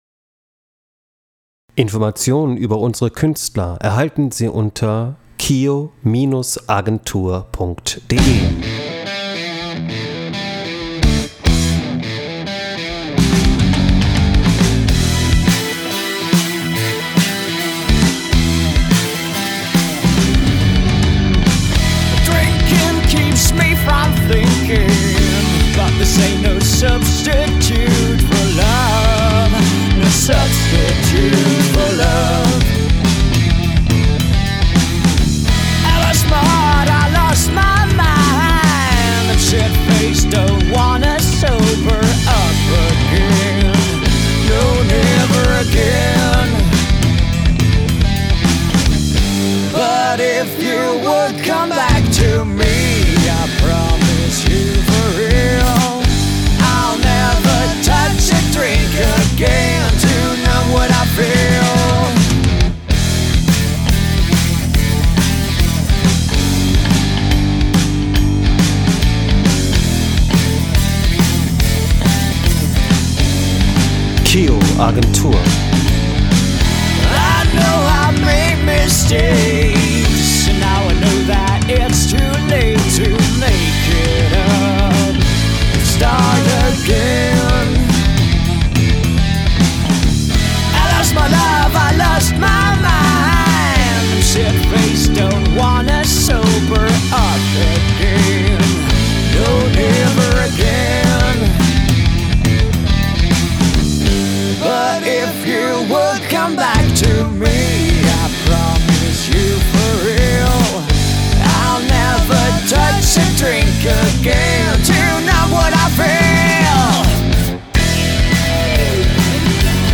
als feste Rock-Formation auf bestem Wege